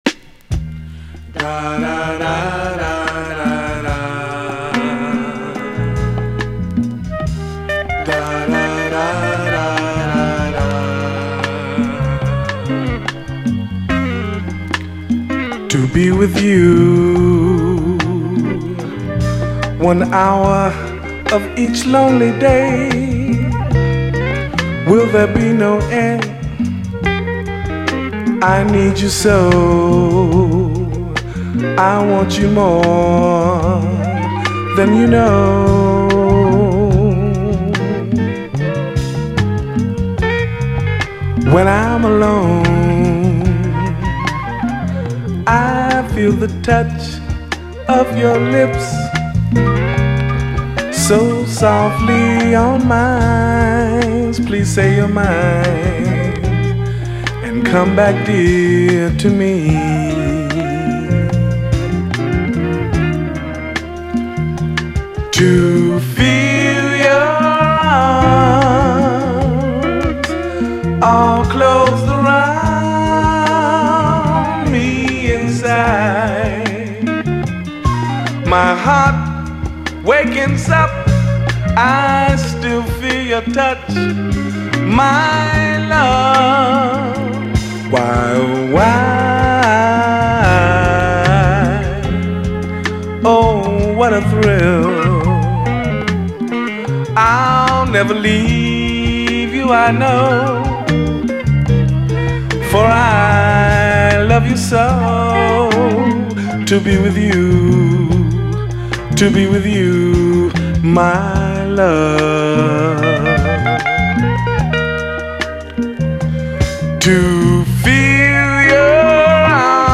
SOUL, 70's～ SOUL, 7INCH
ドチャ甘なローライダー・スウィート・ソウル！
得意の猥雑ストリート・ファンク！中盤以降の優しい泣きメロの展開も渋い！